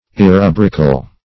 Definition of irrubrical.
Irrubrical \Ir*ru"bric*al\, a.